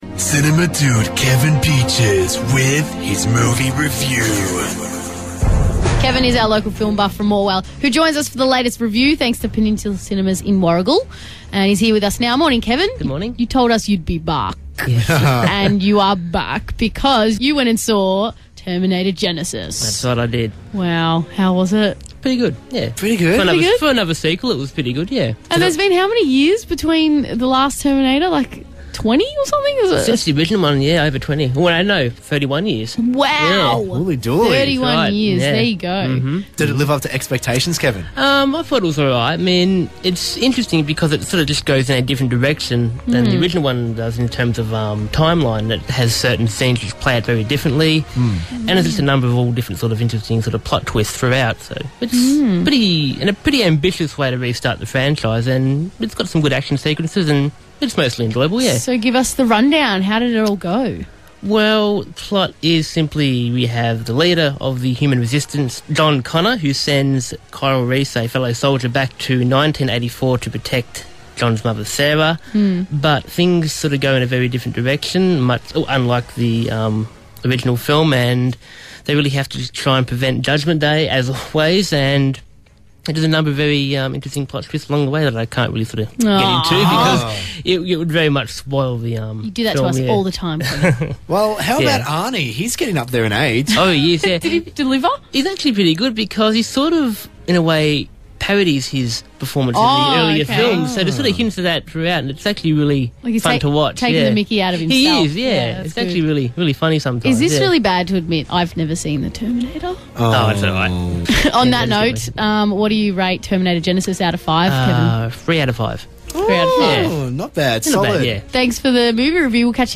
Review: Terminator Genisys (2015)